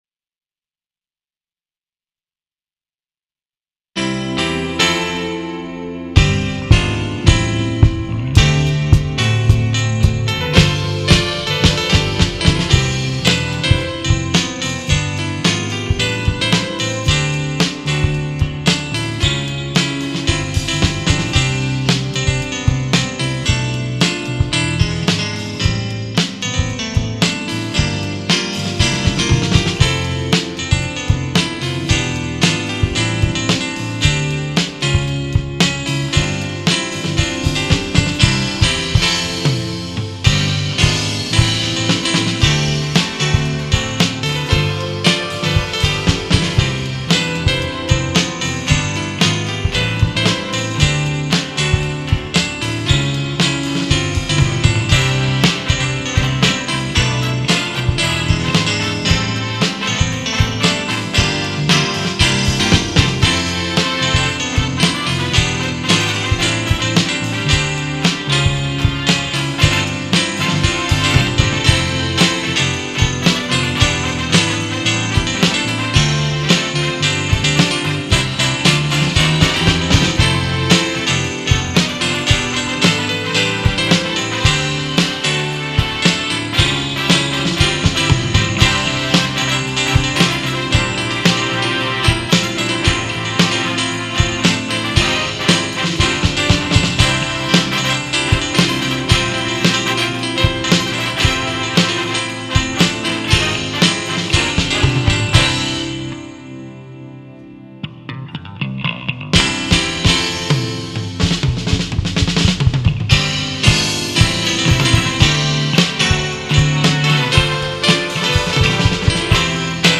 2004년 제 24회 정기 대공연
홍익대학교 신축강당
Rap
어쿠스틱 기타
베이스
드럼
신디사이저